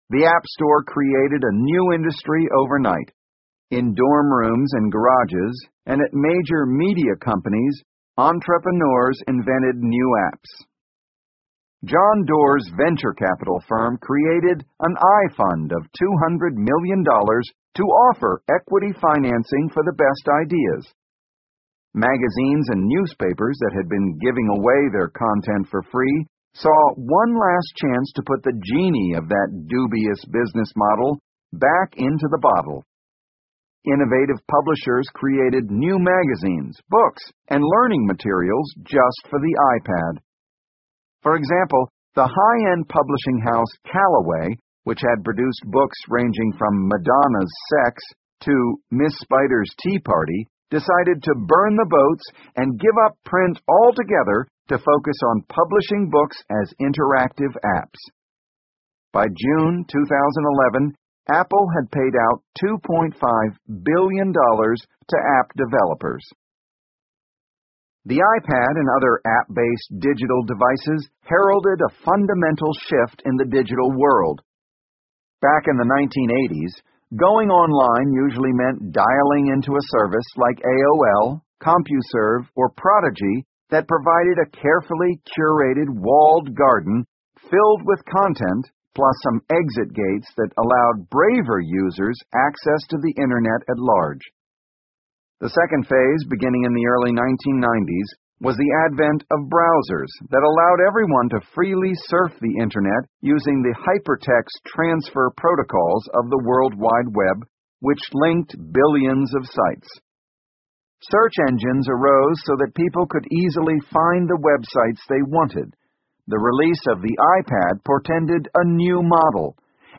在线英语听力室乔布斯传 第698期:应用程序(4)的听力文件下载,《乔布斯传》双语有声读物栏目，通过英语音频MP3和中英双语字幕，来帮助英语学习者提高英语听说能力。
本栏目纯正的英语发音，以及完整的传记内容，详细描述了乔布斯的一生，是学习英语的必备材料。